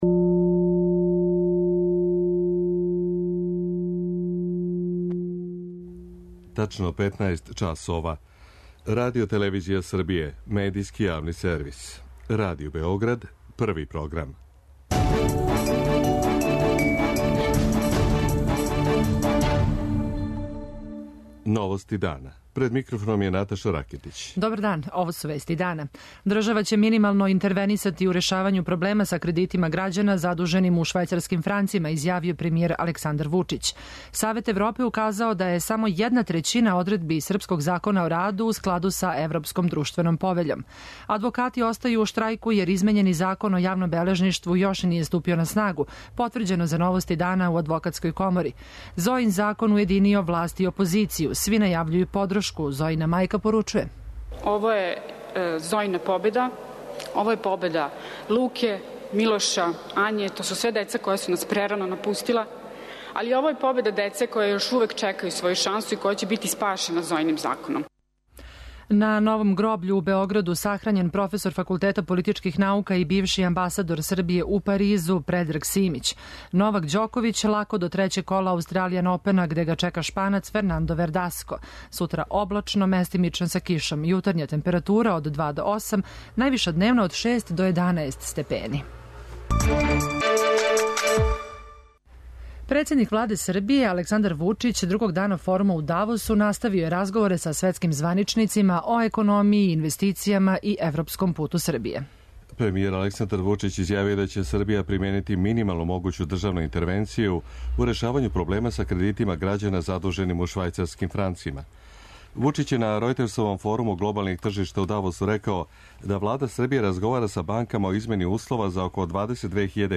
Новости дана